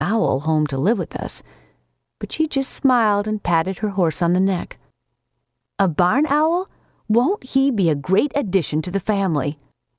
woman_2.wav